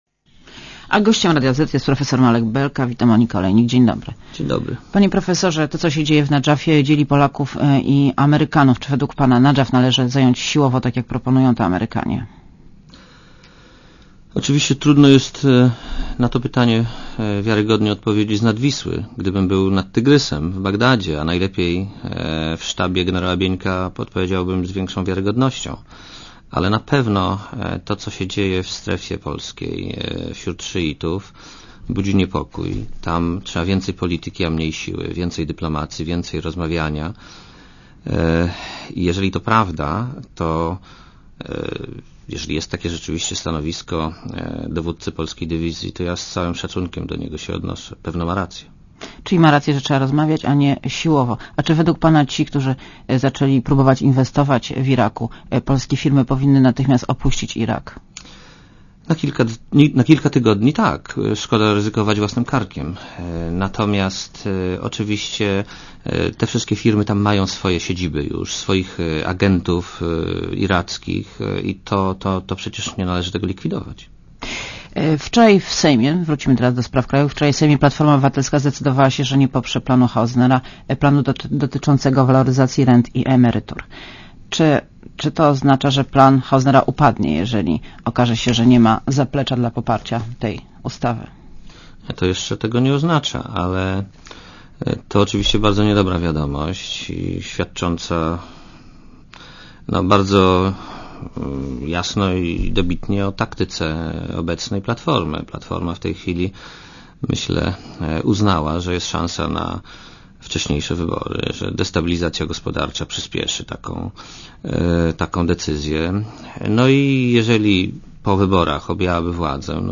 Marek Belka w Radiu Zet (RadioZet)